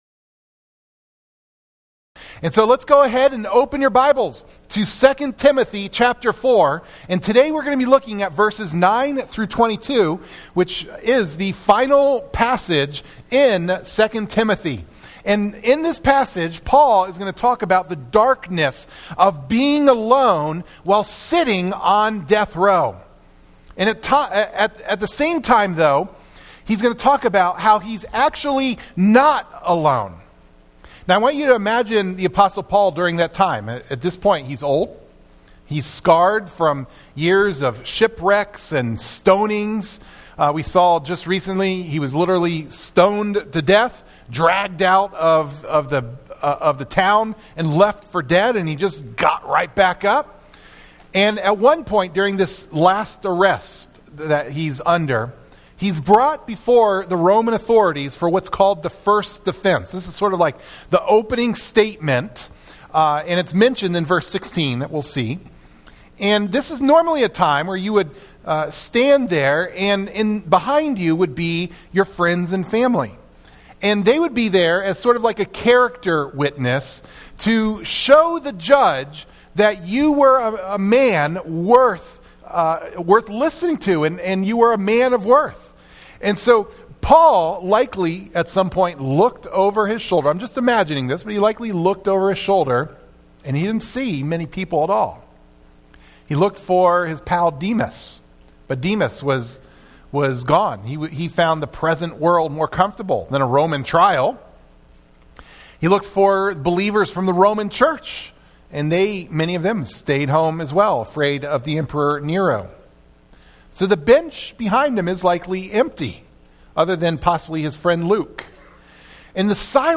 sermon, Discover how Christ’s presence sustains believers through loneliness, suffering, and abandonment in this powerful message from 2 Timothy 4:9–22.